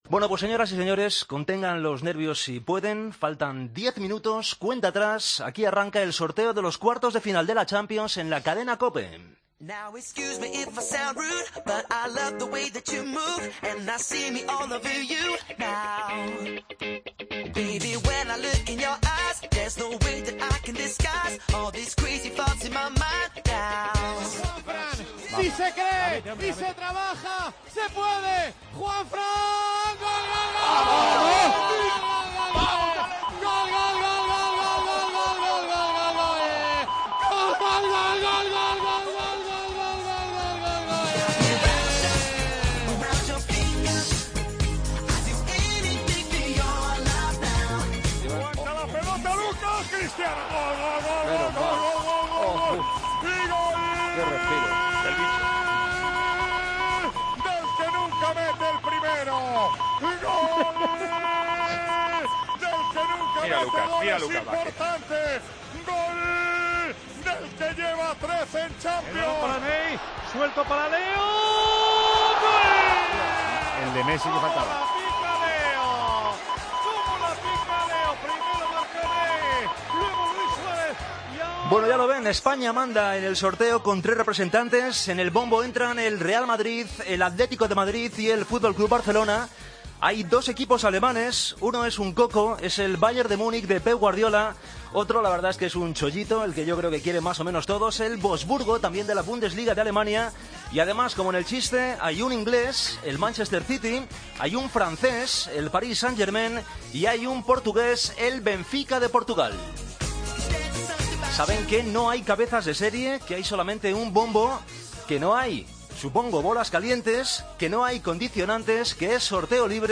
AUDIO: Escucha la retransmisión en la Cadena COPE del sorteo de cuartos de final de la Champions League.